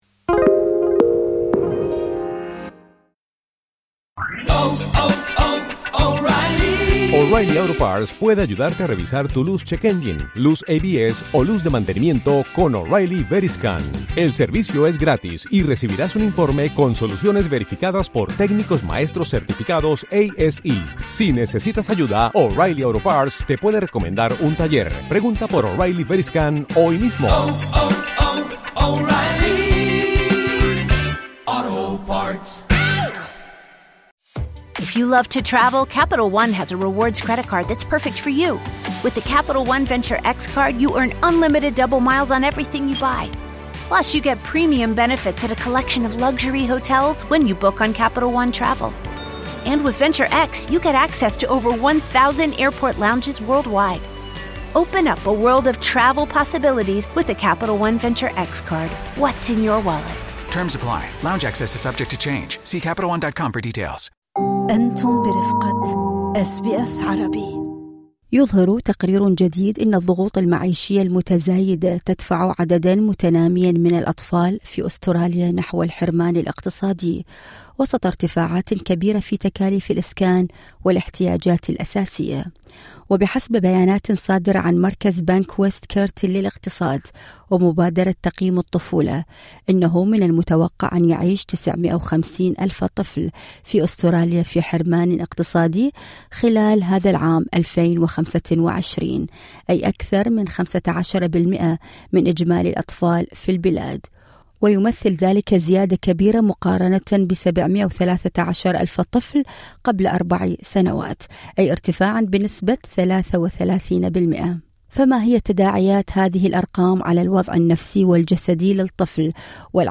لقاءً